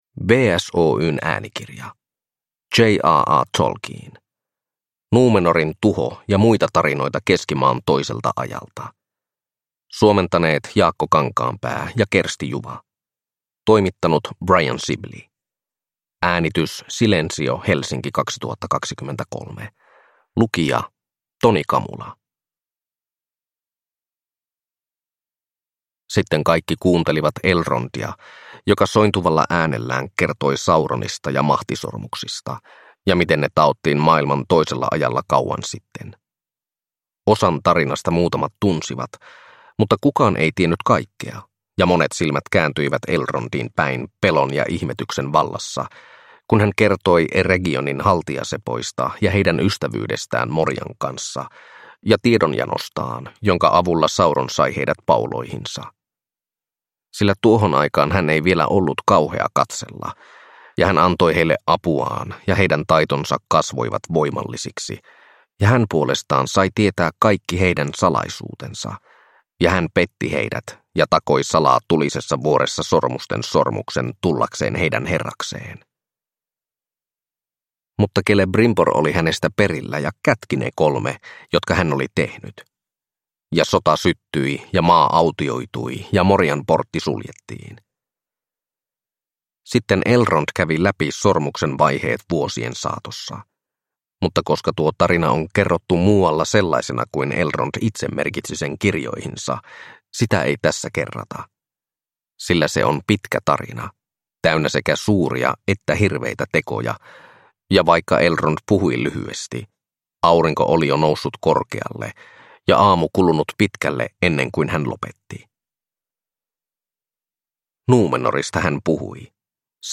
Númenorin tuho – Ljudbok – Laddas ner